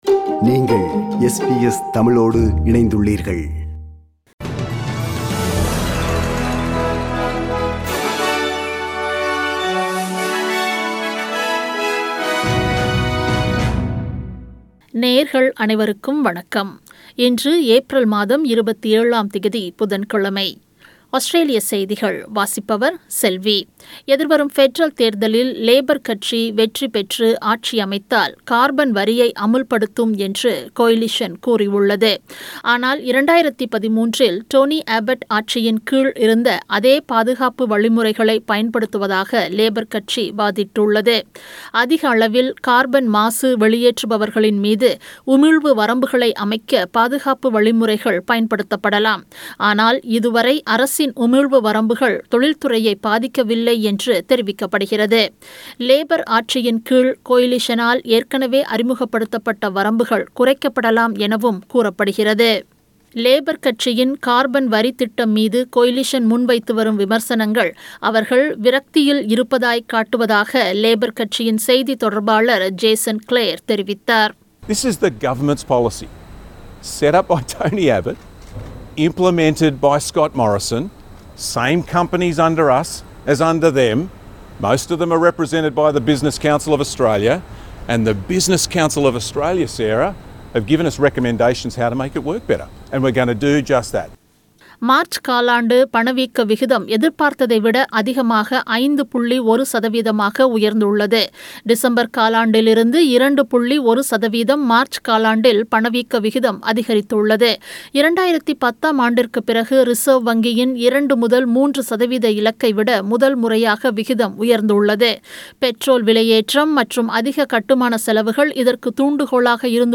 Australian news bulletin for Wednesday 27 April 2022.